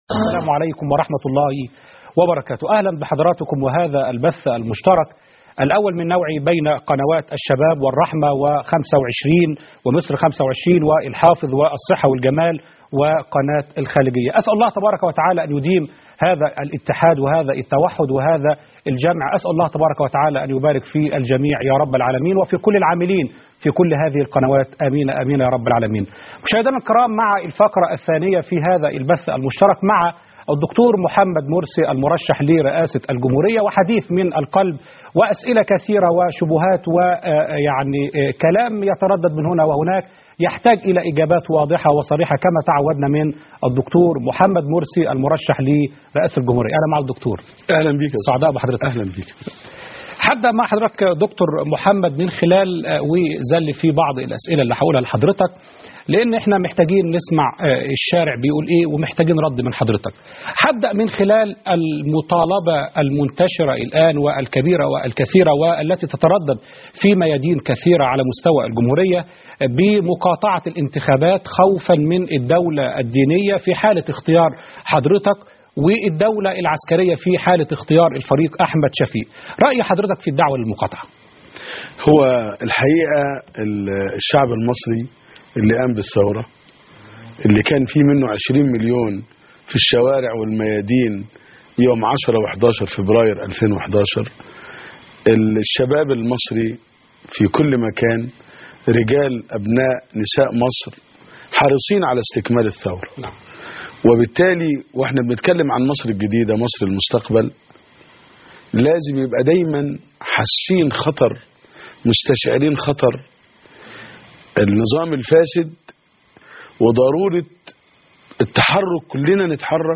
لقاء مع د/ محمد مرسي على عدة قنوات (8/6/2012) - قسم المنوعات